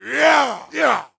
attack_1.wav